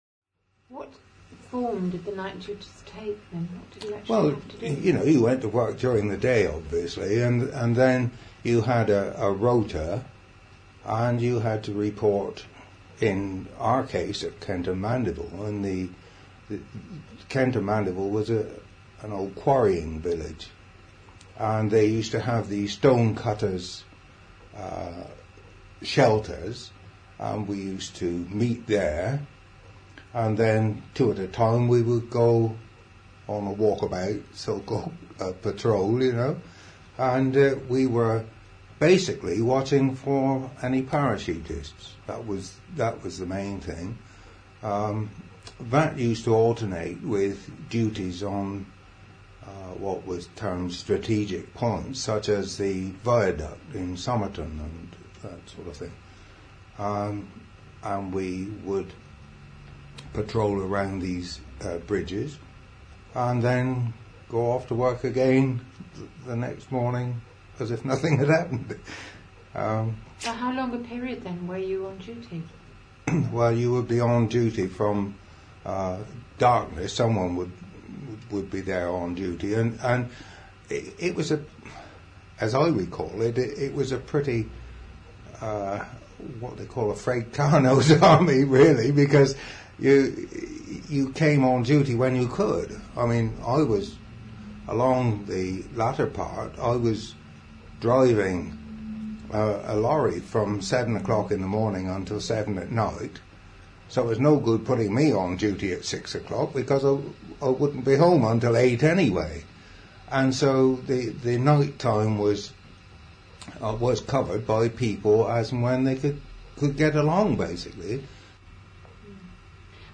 For access to full interview please contact the Somerset Heritage Centre.